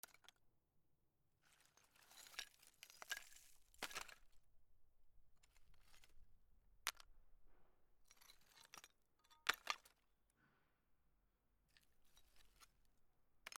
壁 廃材
パラパラ D50